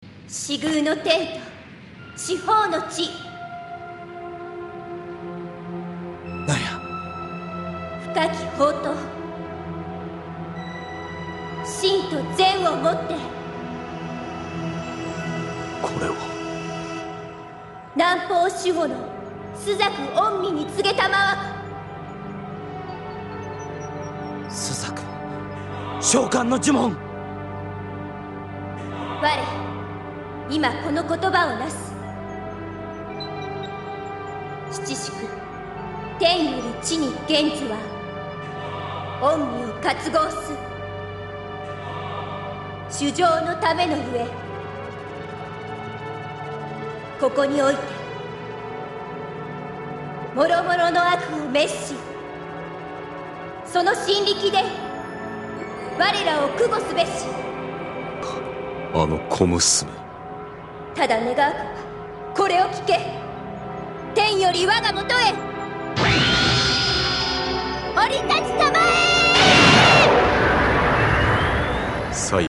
Also in my soundfile part of Nakago got cut off between the last two segments of the spell.
You just can't hear it because of my shoddy editing equipment...